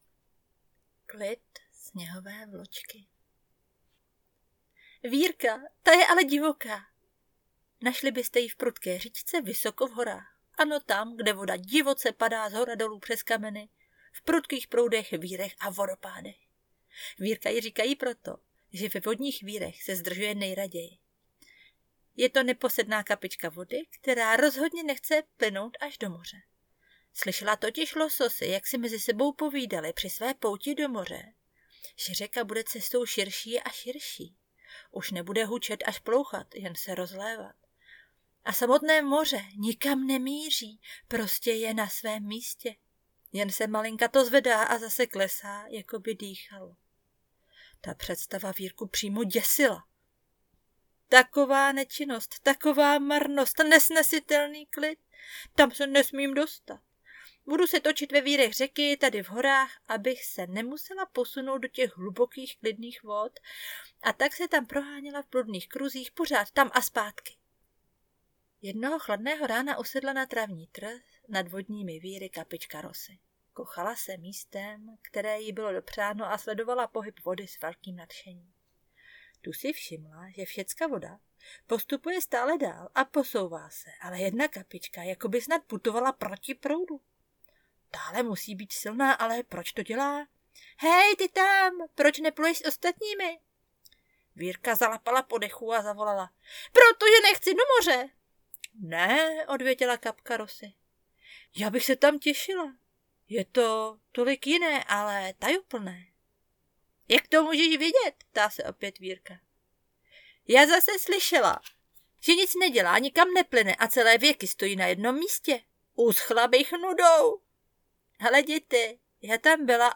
Kompletní je sbírka pohádek. Nahrávala jsem ji sama, není to nic dokonalého, ale má to prožitek a emoci.